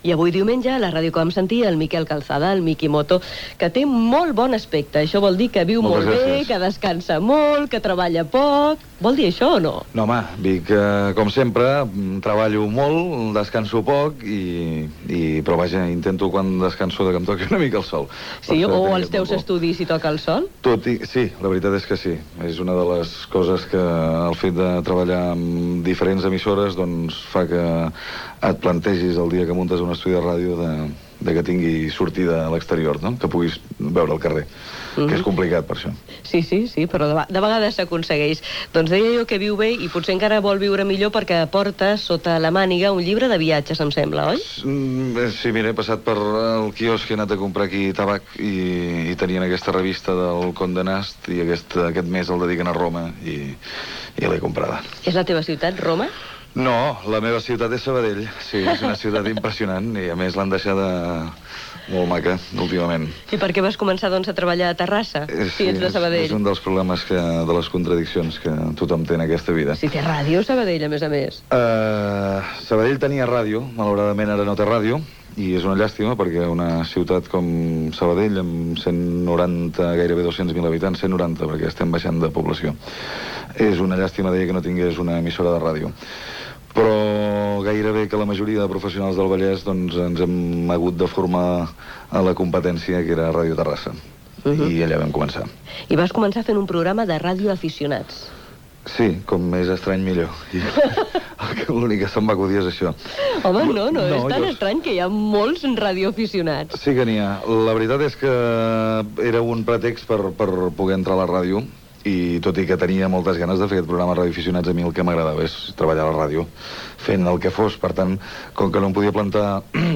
Entrevista a Miquel Calçada "Mikimoto": un llibre de viatges que porta sobre Roma, la ciutat de Sabadell, els seus inicis a Ràdio Terrassa, les primeres paraules a Catalunya Ràdio
Divulgació